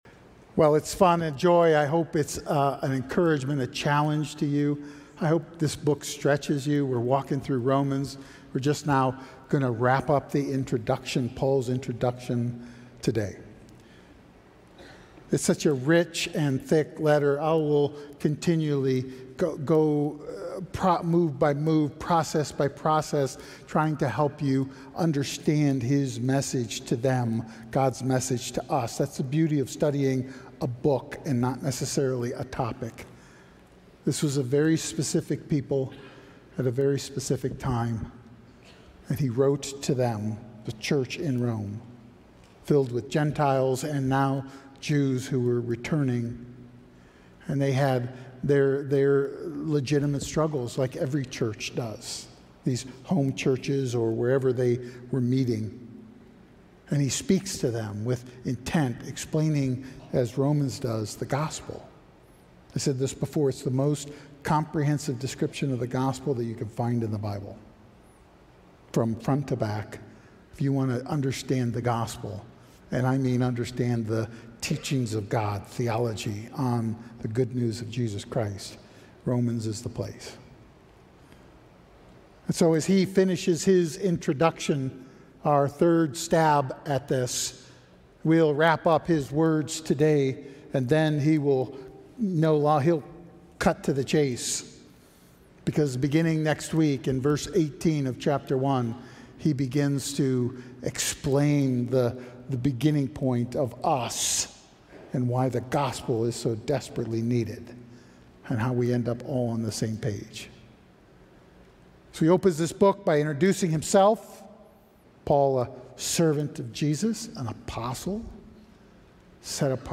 The Power of God Audio File Sermon Notes More From This Series Farewell...